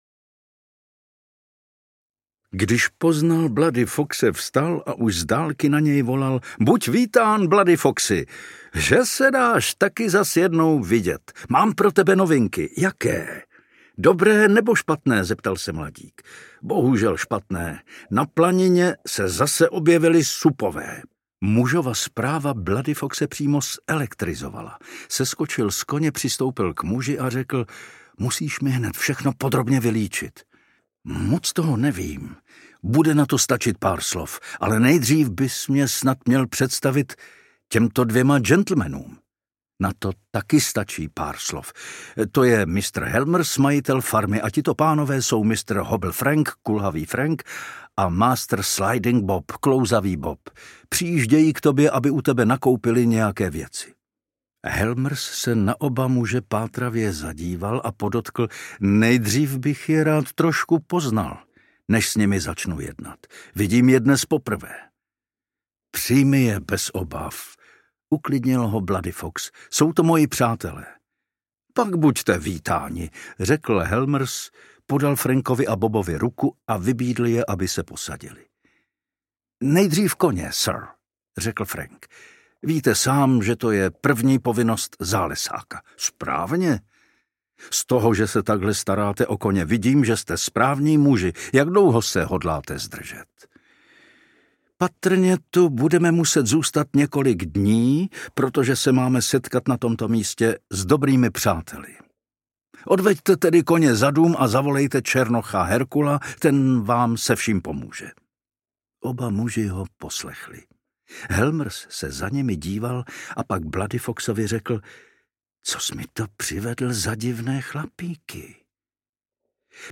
Duch Llana Estacada audiokniha
Ukázka z knihy
• InterpretPavel Soukup